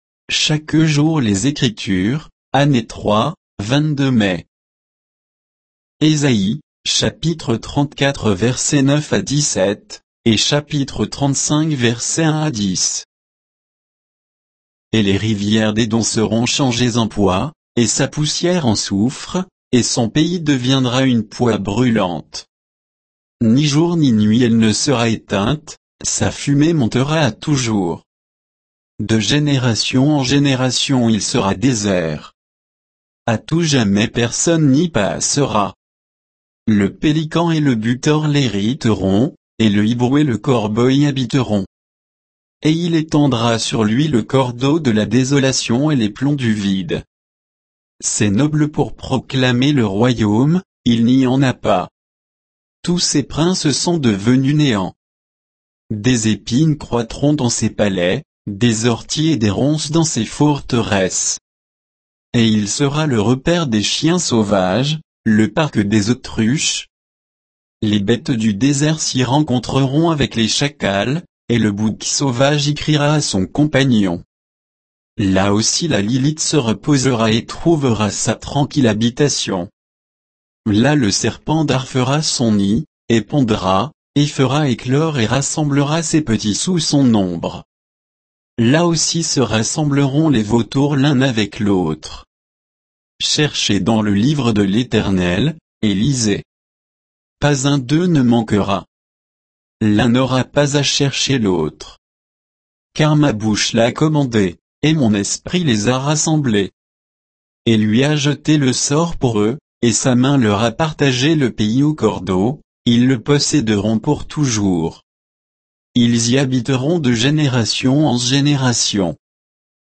Méditation quoditienne de Chaque jour les Écritures sur Ésaïe 34, 9 à 35, 10